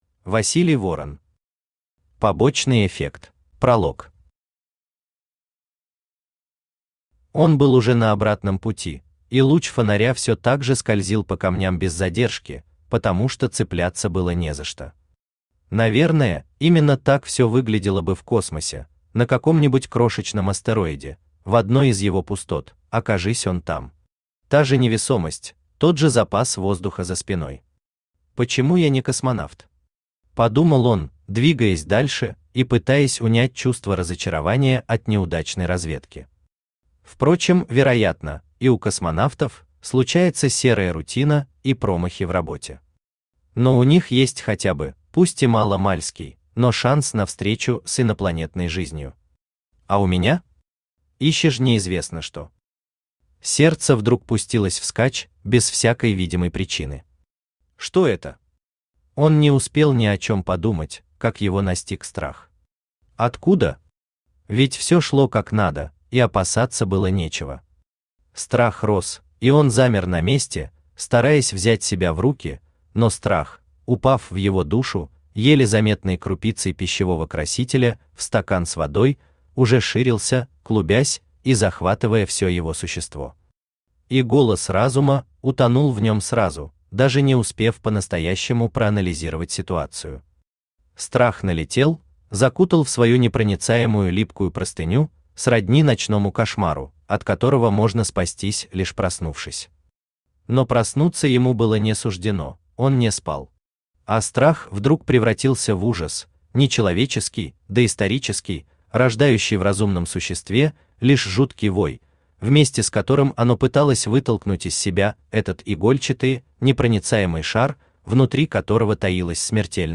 Аудиокнига Побочный эффект | Библиотека аудиокниг
Aудиокнига Побочный эффект Автор Василий Ворон Читает аудиокнигу Авточтец ЛитРес.